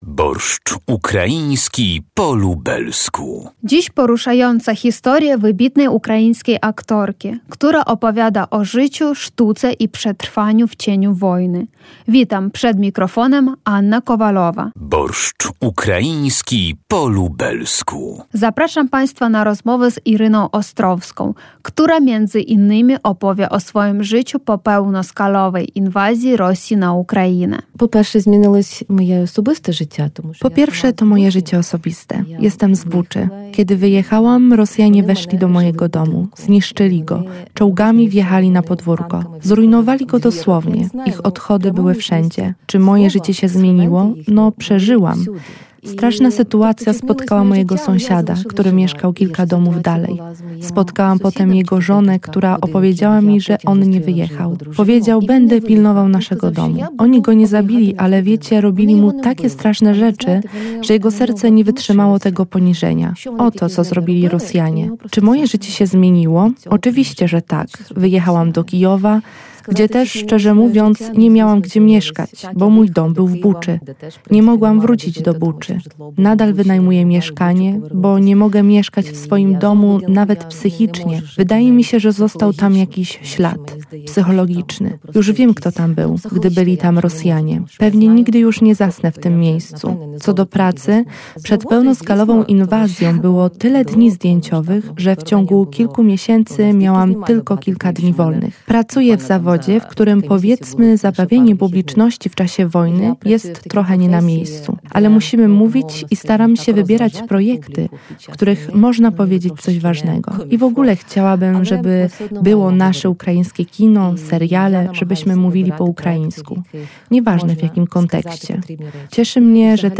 Zapraszam Państwa na rozmowę